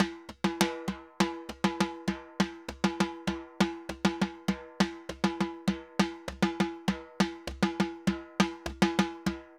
Timba_Baion 100_1.wav